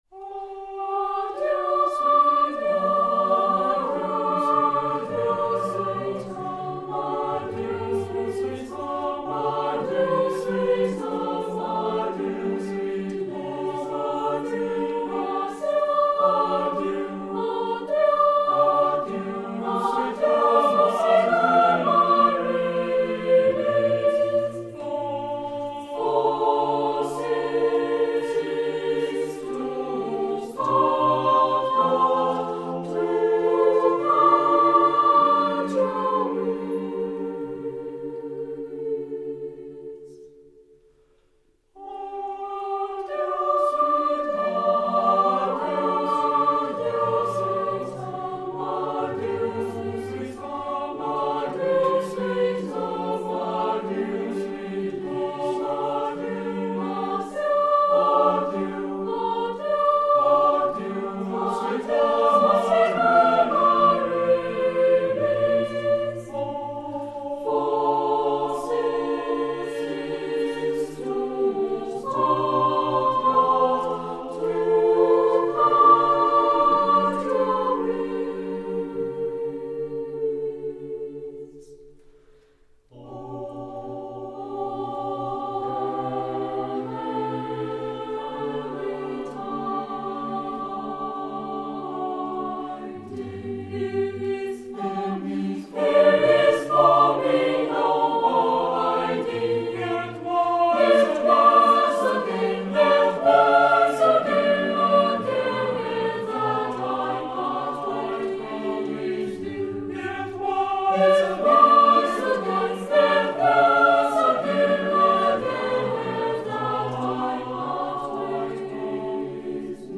录音地址:石碑胡同中国唱片社录音棚
【欧洲牧歌部分】